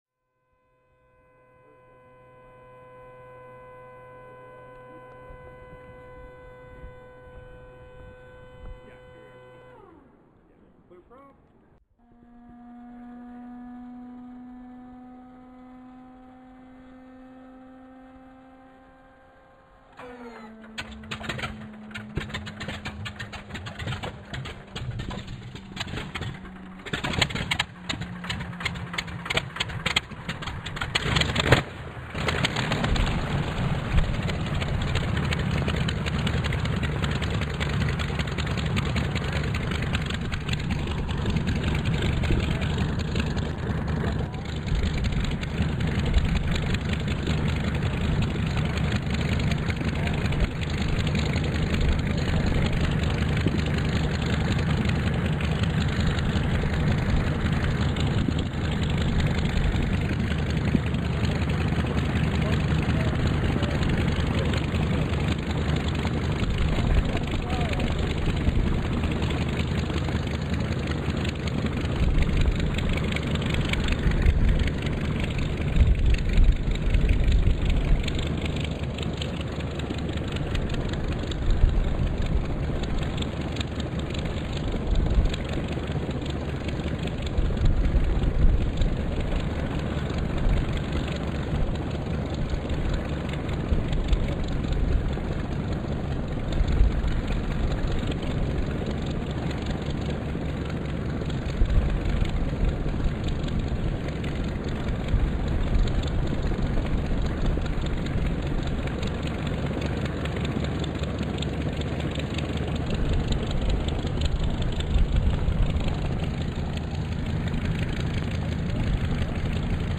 Cottage Grove, OR.
Engine Start - Runup - Takeoff - Flyby